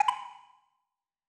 Sound / Effects / UI / Retro6.wav